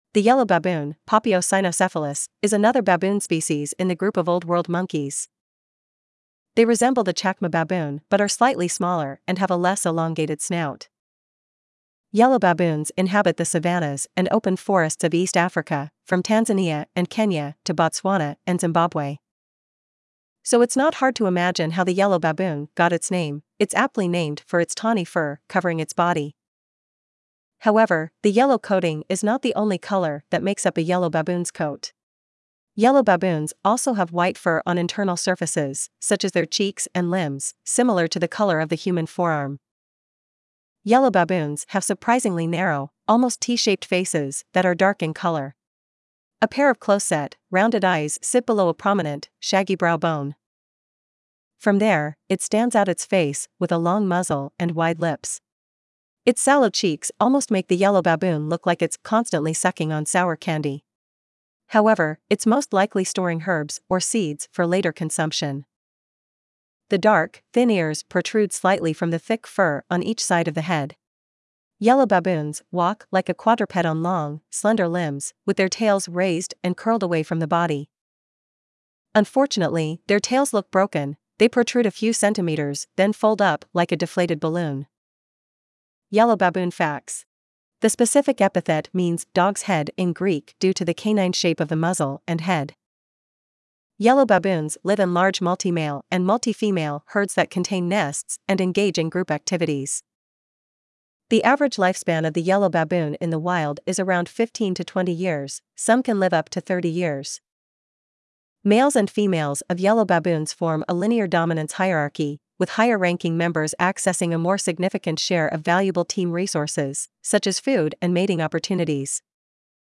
Yellow Baboon
Yellow-Baboon.mp3